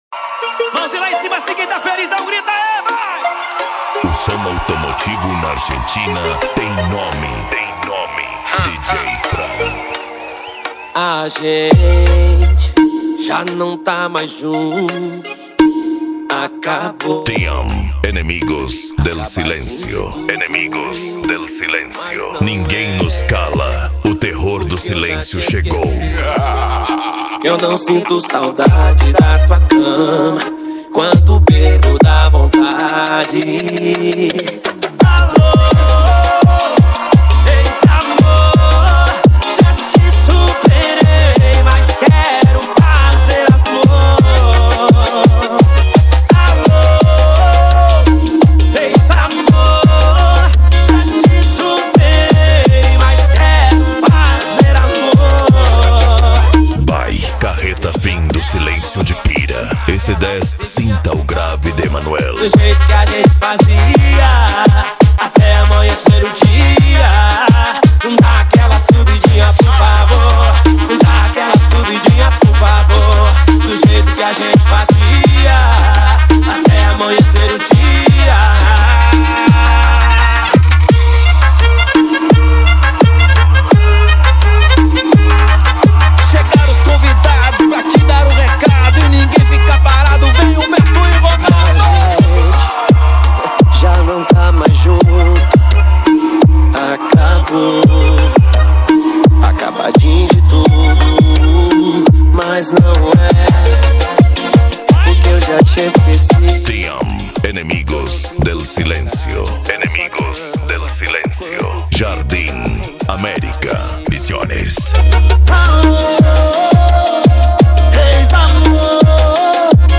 Bass
Mega Funk
Remix